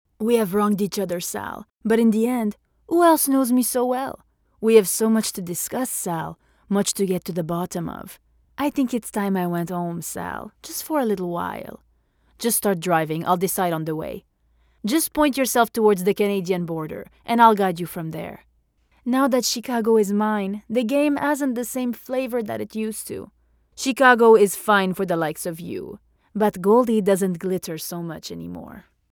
Has Own Studio
french | character